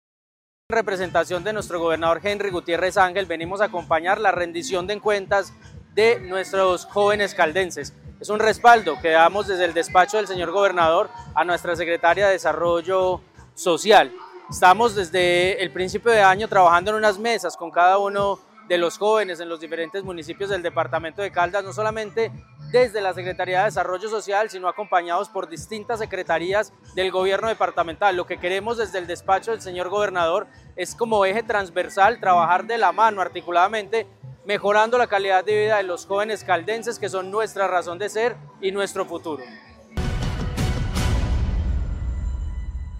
Cerca de 100 jóvenes de todo Caldas llegaron hasta Cameguadua, en Chinchiná, para asistir a la Rendición de Cuentas de Juventud 2025, liderada por la Secretaría de Integración y Desarrollo Social del departamento.
Ronald Fabián Bonilla Ricardo, secretario Privado.